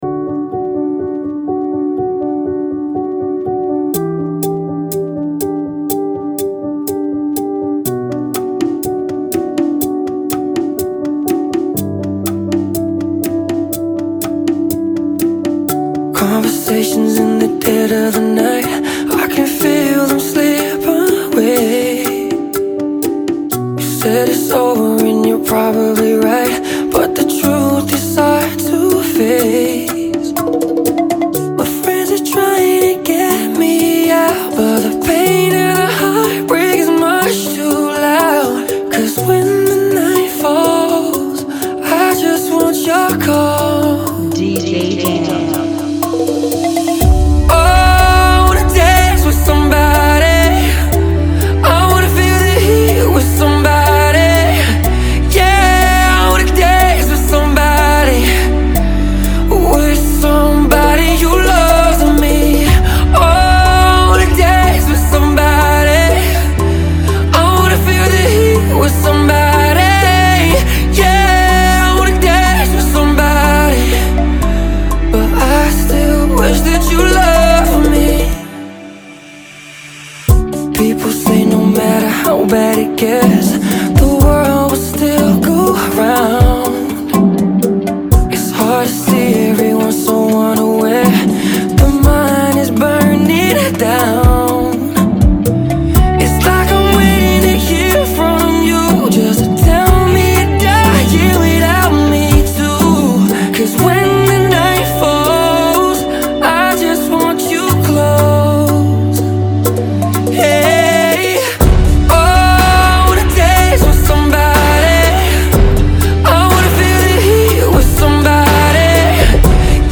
122 BPM
Genre: Bachata Remix